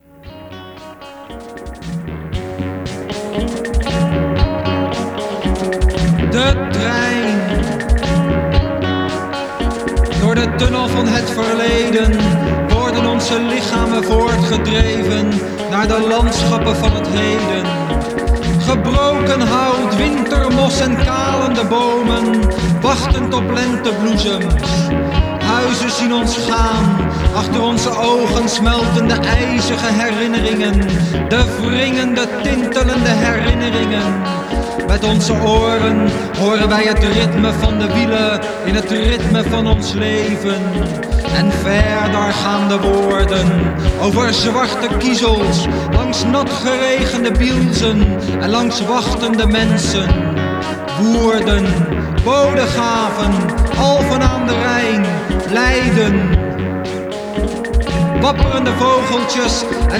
spoken-word